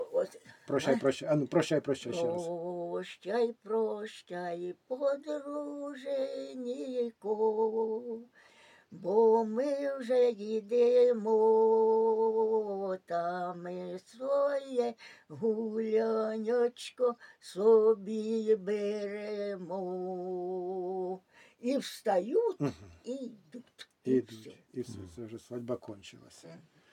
ЖанрВесільні
Місце записуc. Бондури (х. Скрилі), Полтавський район, Полтавська обл., Україна, Полтавщина